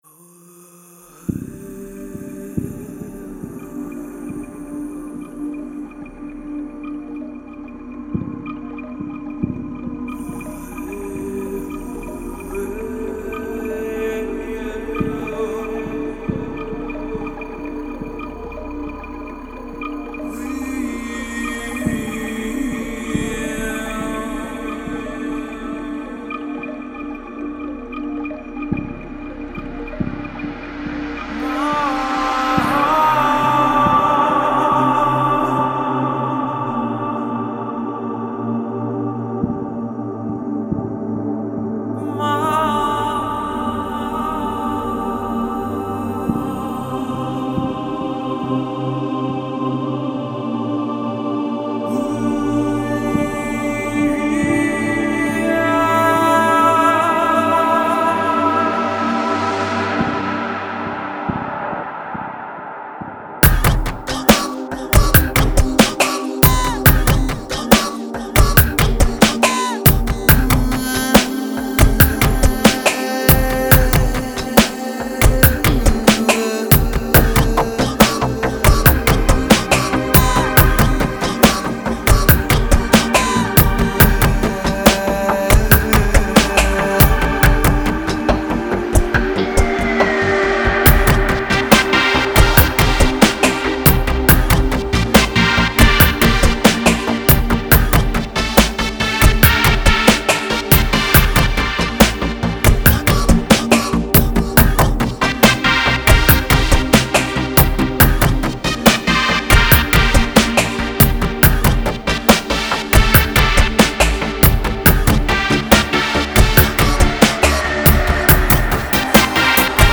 New Age Мистическая музыка Ambient